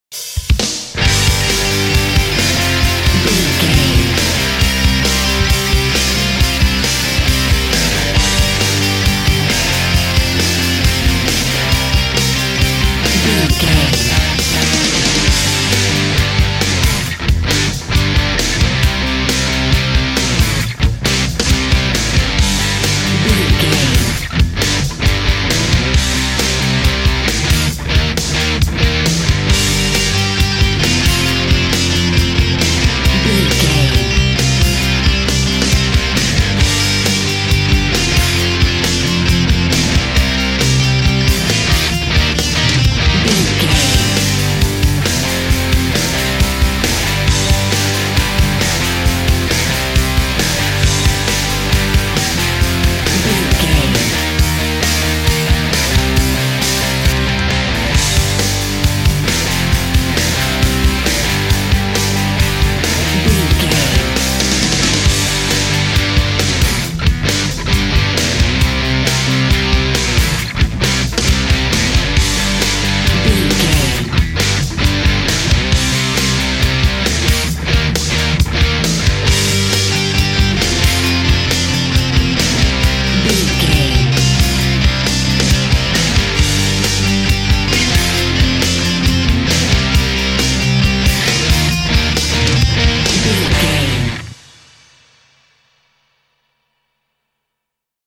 Ionian/Major
drums
electric guitar
bass guitar
Sports Rock
pop rock
hard rock
lead guitar
aggressive
energetic
intense
powerful
nu metal
alternative metal